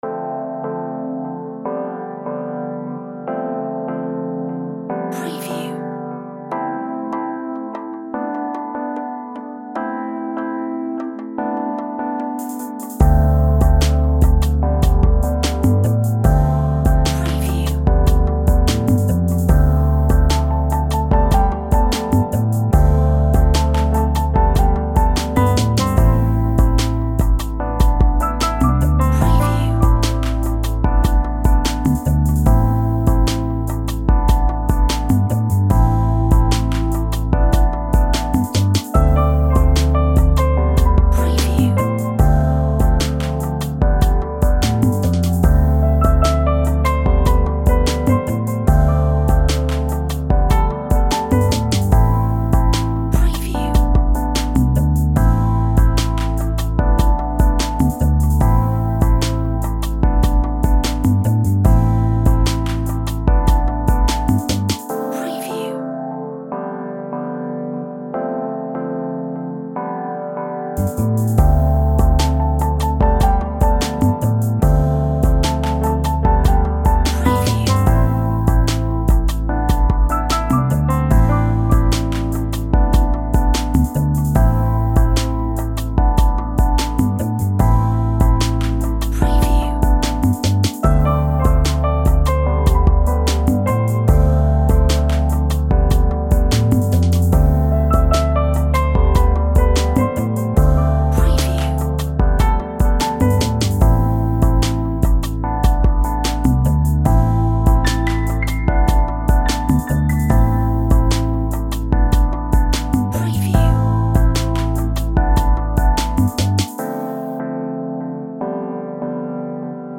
Sunkissed bliss